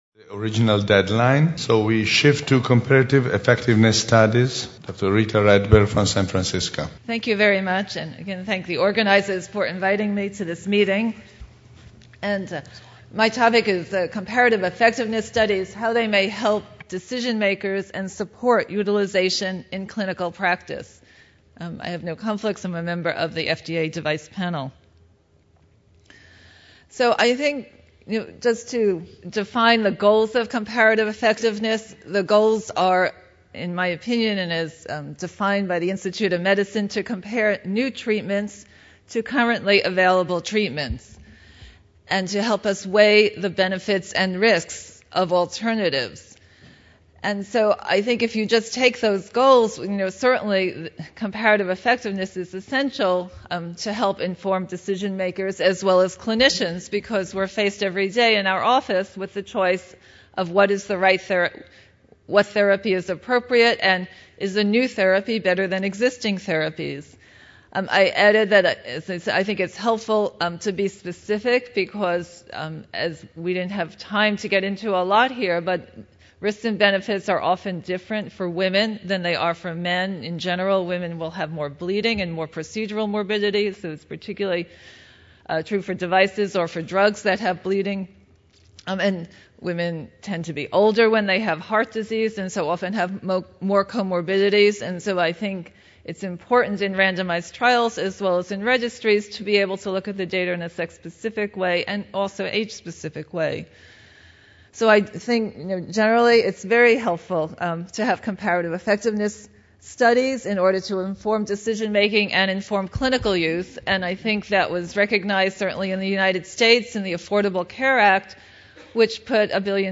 Cardiovascular Clinical Trialists (CVCT) Forum – Paris 2012 - Lunch Session 1 : Comparative effectiveness studies